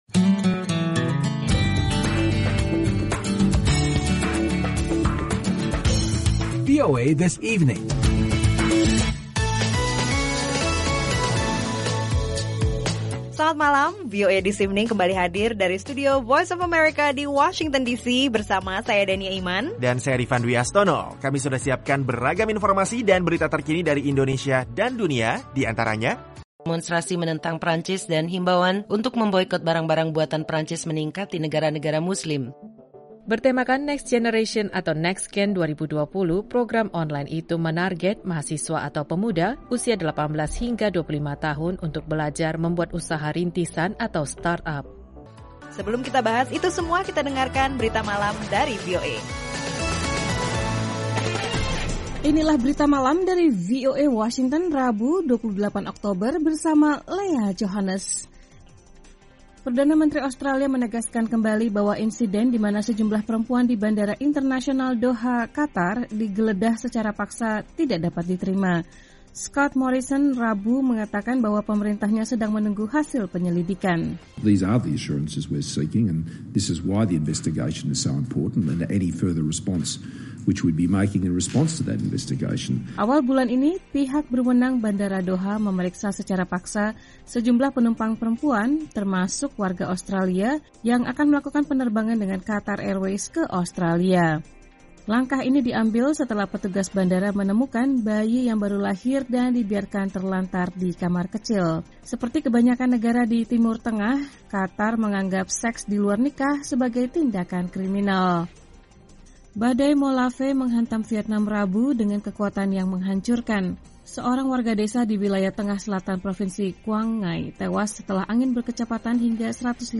Akhiri kesibukan hari kerja Anda dengan rangkuman berita terpenting dan informasi menarik yang memperkaya wawasan Anda dalam VOA This Evening.